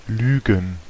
Ääntäminen
IPA: /ʁu.le/